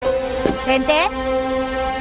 thenthen Meme Sound Effect